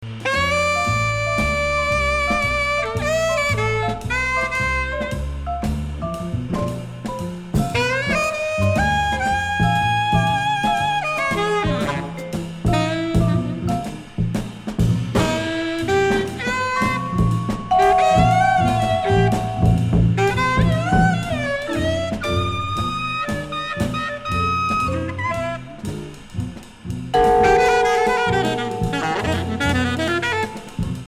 jazz
jazz avant garde